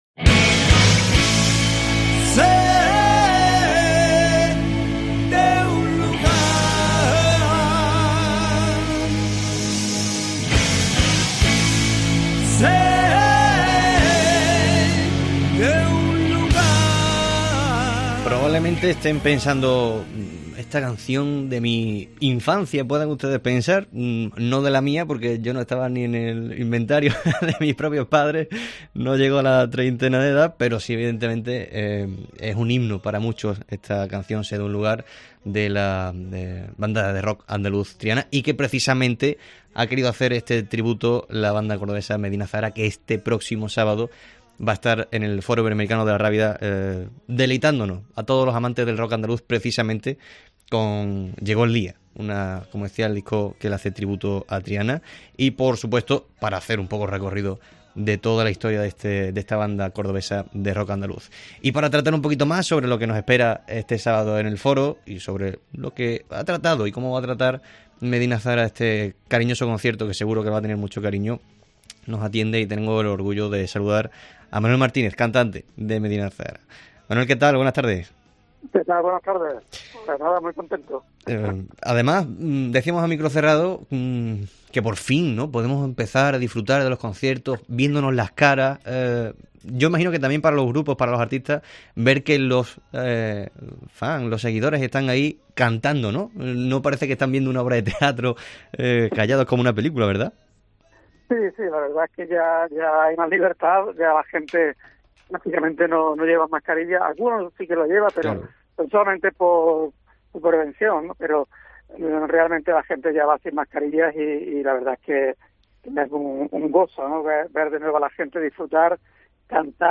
Entrevista a Manuel Martínez, vocalista de Medina Azahara